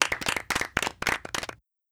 cheers.wav